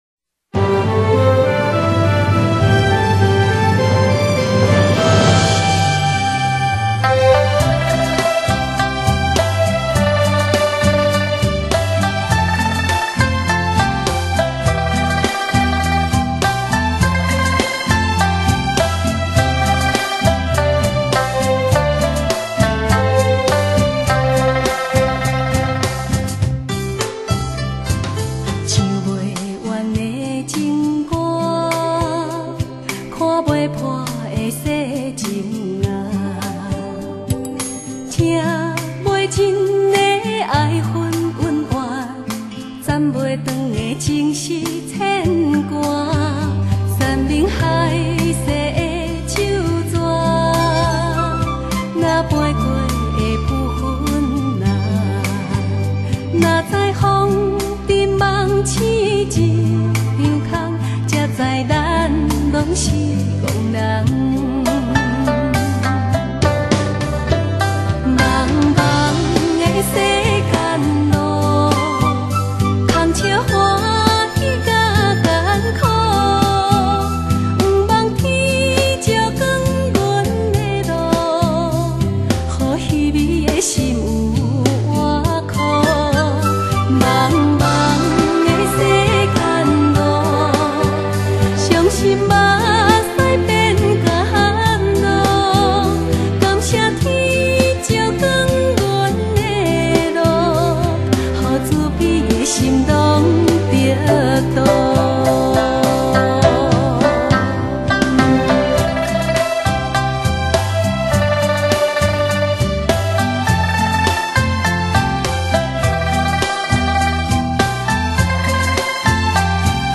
她的歌声让人有如置身一片开阔的绿地，自然中带给人宁静与平和。